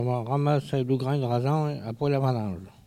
collecte de locutions vernaculaires